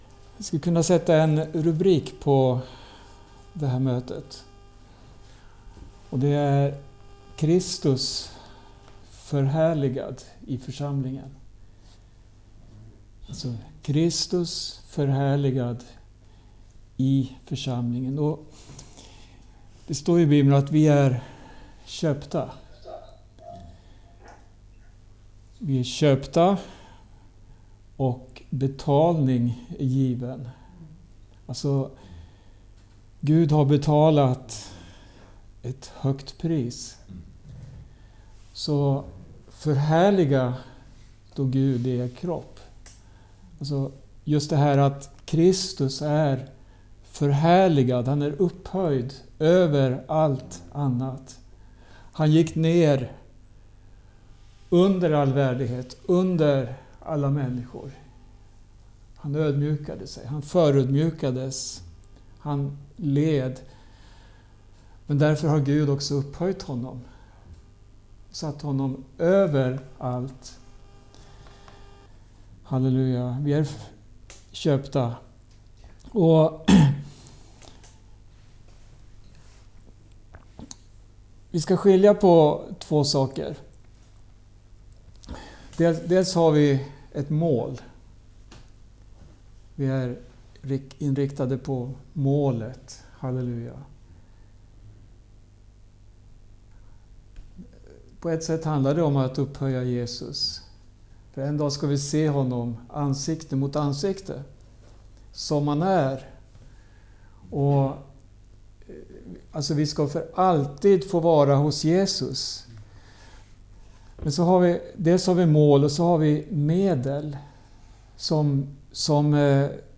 Bibelstudium inspelat hos församlingen i Skälby, Järfälla 21 maj 2025